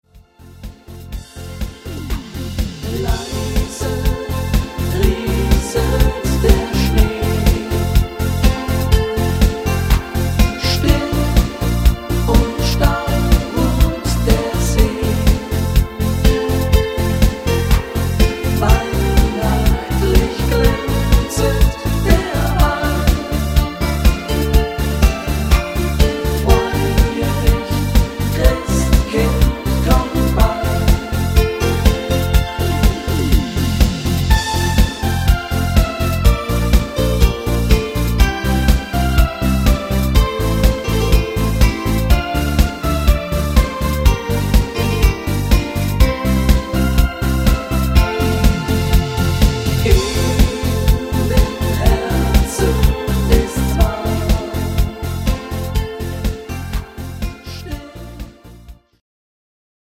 im Schlagersound